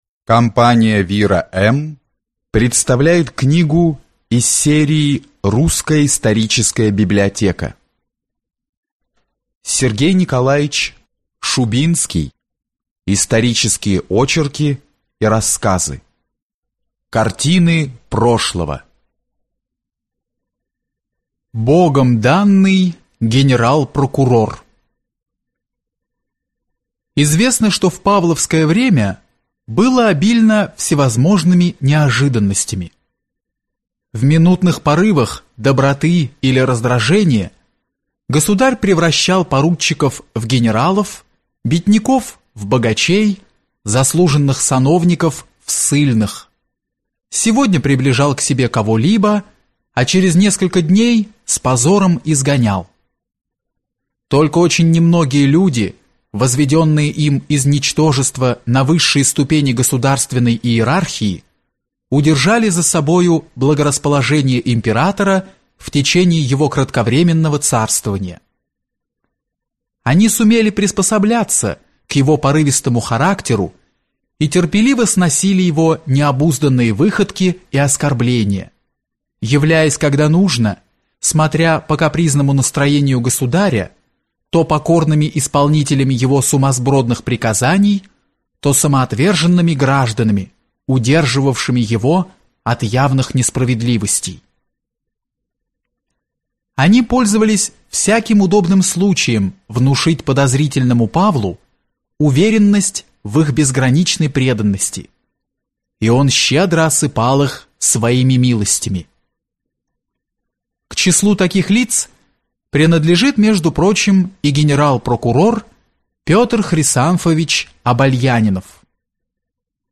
Аудиокнига Картины прошлого | Библиотека аудиокниг